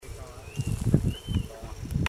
Nothoprocta cinerascens
Nome em Inglês: Brushland Tinamou
Fase da vida: Adulto
Detalhada localização: Jardín Botánico Provincial
Condição: Selvagem
Certeza: Gravado Vocal